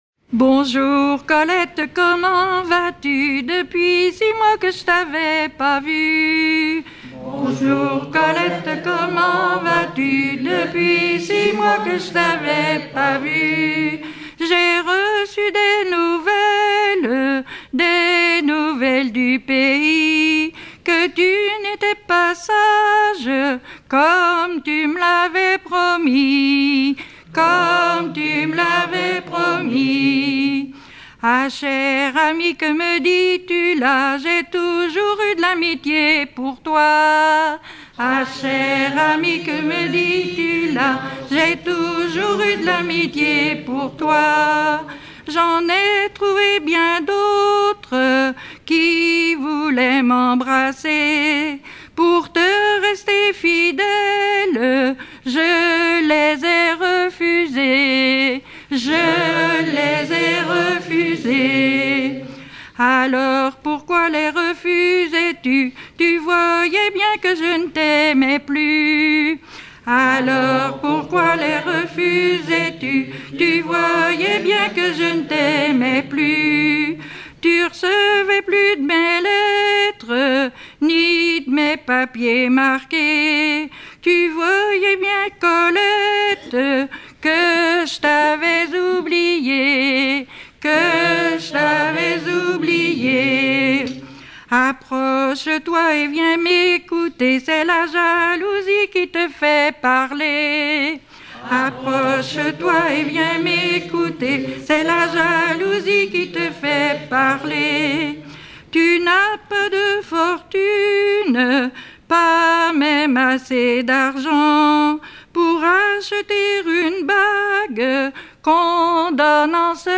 Monsireigne
Genre strophique